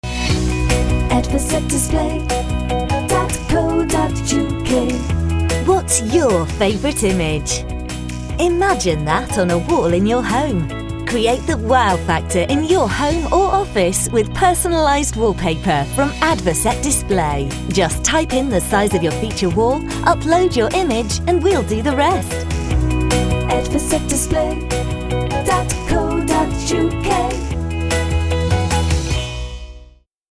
We're on the Radio!
adverset display's new radio commercial (complete with catchy jingle!) is now live and will be broadcast over the next three months on Yorkshire Coast Radio. The 30 second promotional piece features our bespoke wallpaper service and is designed to 'spread the word' on this fantastic product and also how simple it is to order our personalised wallcoverings on-line.